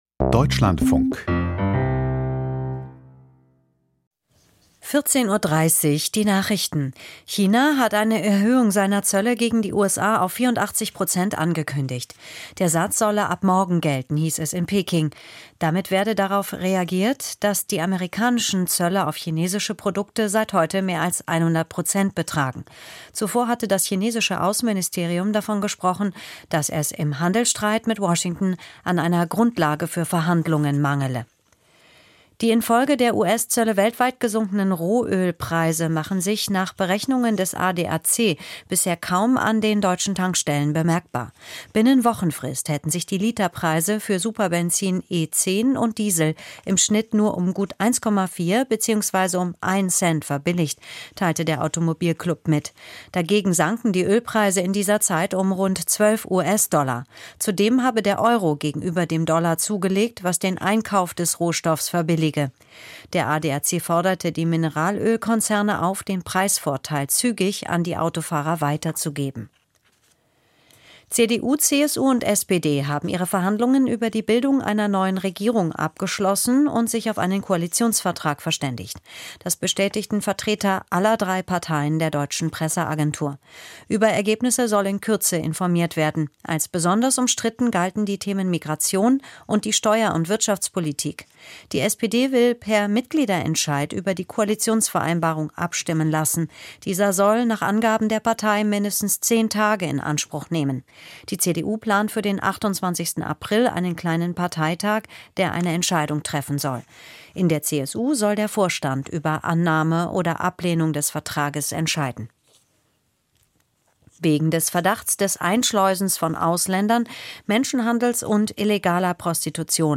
Die Deutschlandfunk-Nachrichten vom 09.04.2025, 14:30 Uhr